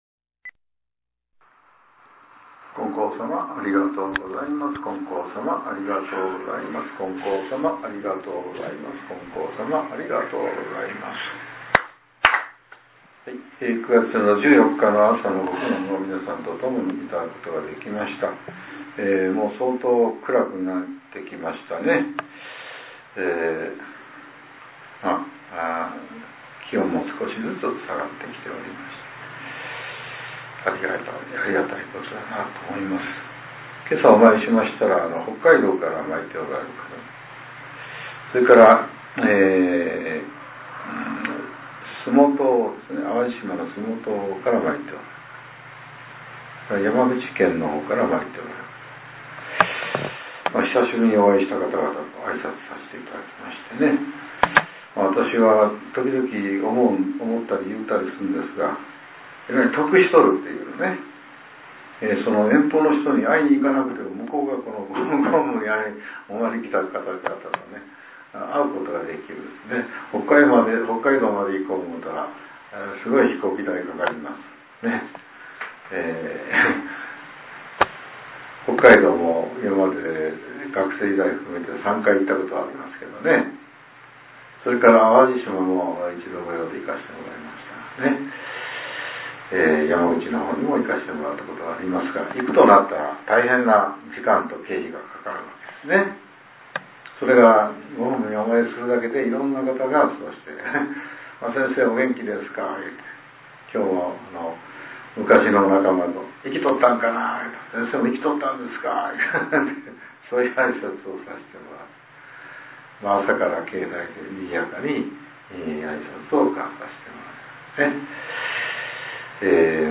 令和７年９月１４日（朝）のお話が、音声ブログとして更新させれています。